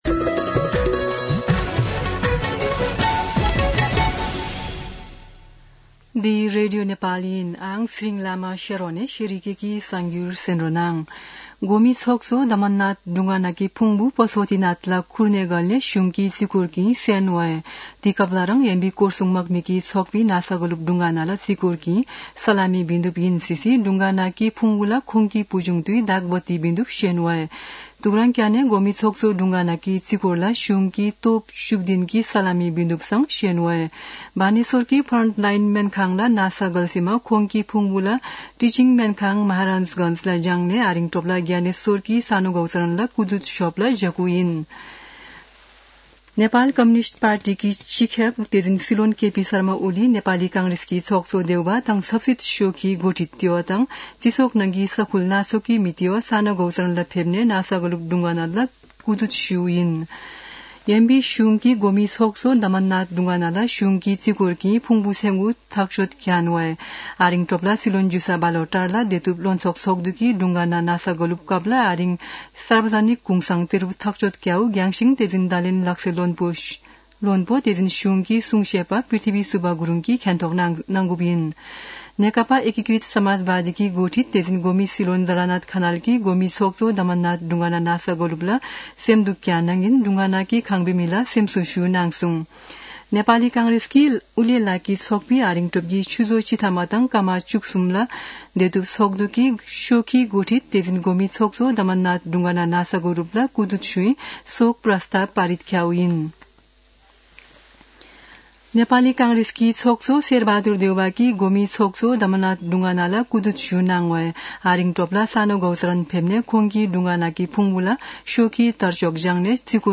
शेर्पा भाषाको समाचार : ४ मंसिर , २०८१
Sherpa-News-03.mp3